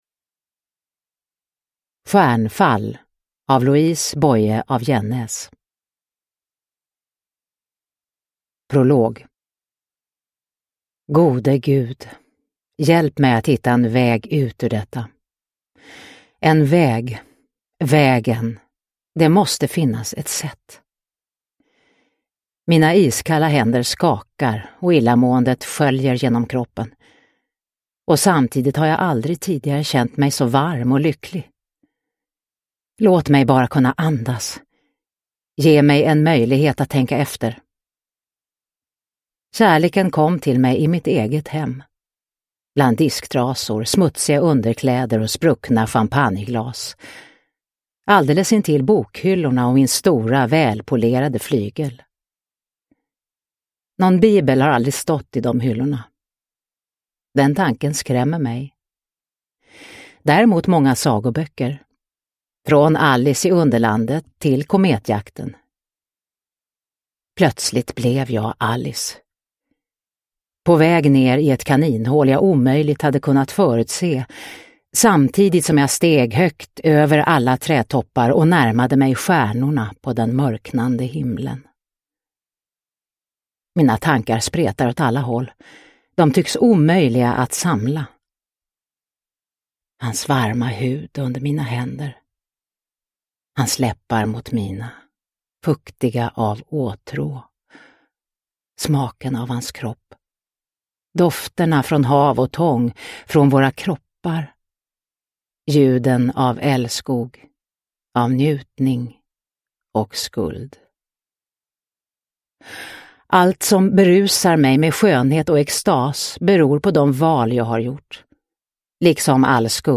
Stjärnfall – Ljudbok – Laddas ner